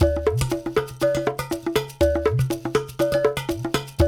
120 -UDU 03R.wav